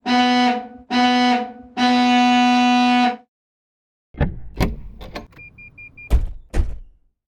Car sequence
Car ding door horn sound effect free sound royalty free Sound Effects